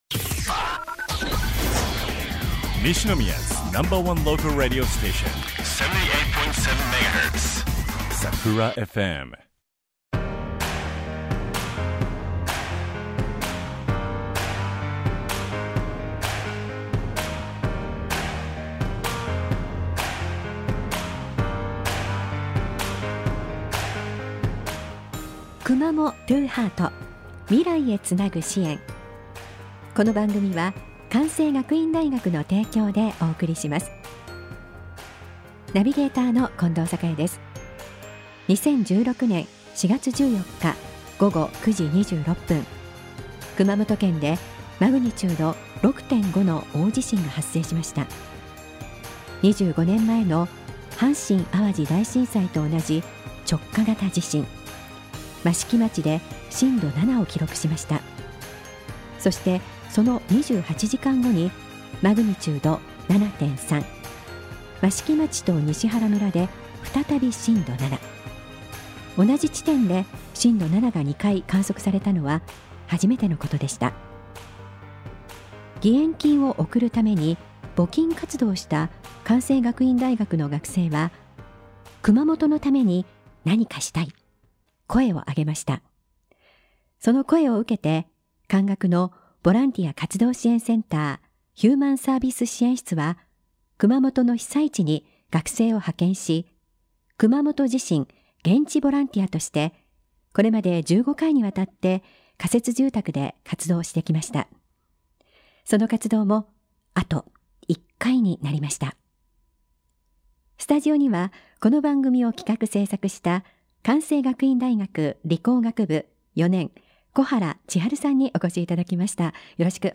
学生たちが自らの声や言葉で熊本や「支援」のことを伝える1時間番組です。